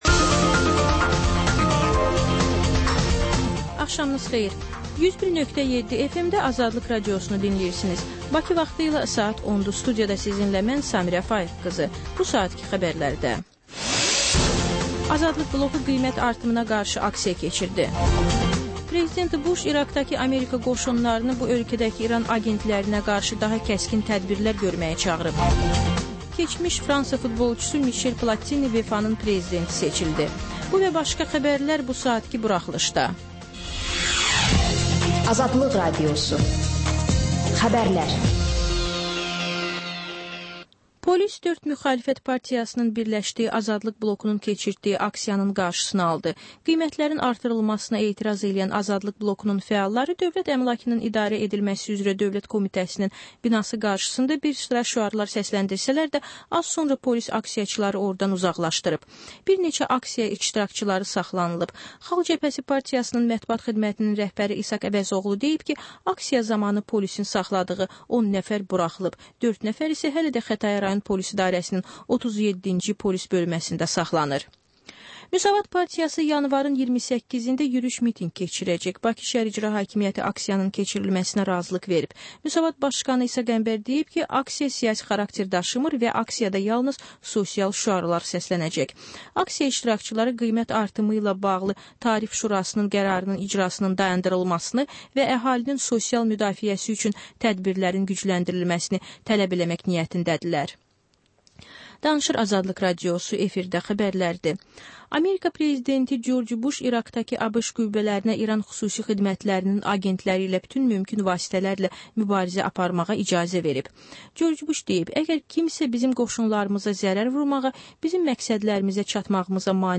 Xəbərlər, reportajlar, müsahibələr. Və sonda: Qlobus: Xaricdə yaşayan azərbaycanlılar barədə xüsusi veriliş.